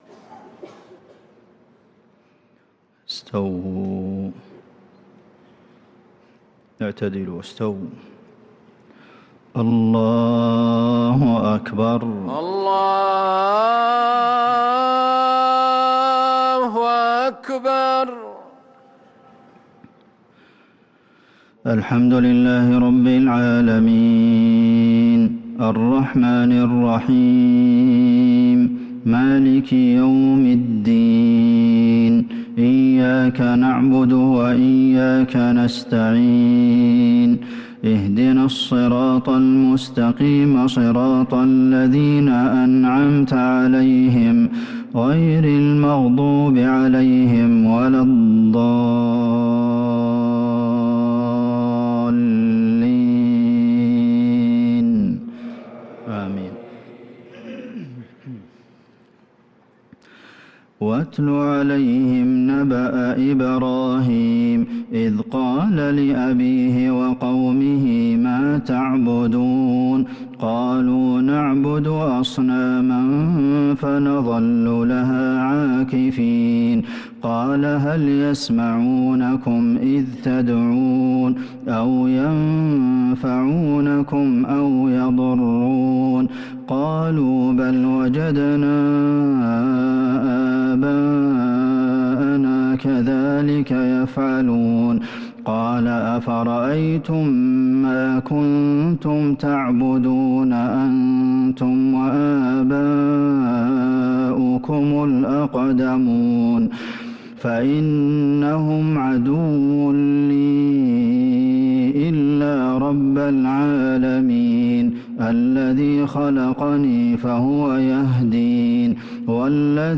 صلاة العشاء للقارئ عبدالمحسن القاسم 5 شوال 1443 هـ
تِلَاوَات الْحَرَمَيْن .